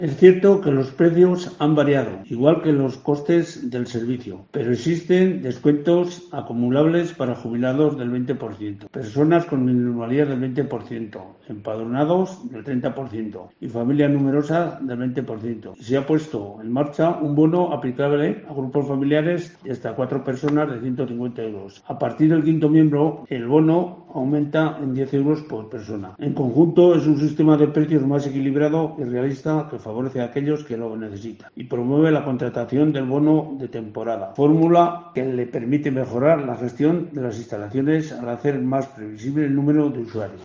Además, el alcalde de Cuzcurrita, Román Urrecho, avanza en COPE Rioja que se ha puesto en marcha un bono aplicable a grupos familiares de hasta 4 personas a un precio de 150 euros.